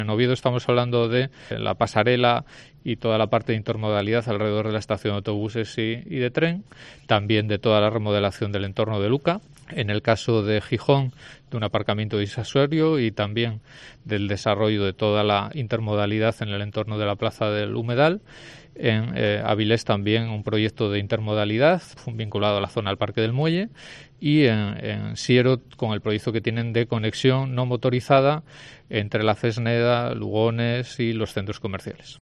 Alejandro Calvo explica los proyectos más importantes